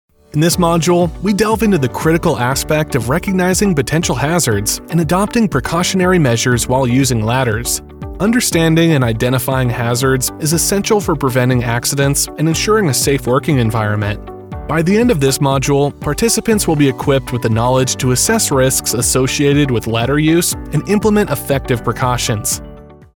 Male
Safety Elearning Course Module
Words that describe my voice are young voice over, american voice over, male voice over.